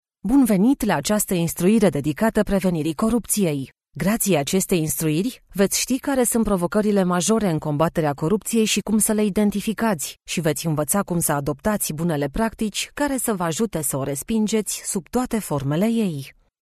Get the distinctive native Romanian voice artist for your project that stays on people's minds and has them telling others about it.
Sprechprobe: Industrie (Muttersprache):
PITCH: mid-range, female, 30-50 yrs. TONE: dynamic, relatable, pleasant ACCENT: neutral Romanian, English with an Eastern European Accent My custom-built home studio is fully connected for directed sessions, I record with a NEUMANN TLM 107 Microphone and Scarlet2i2 generation 4 interface.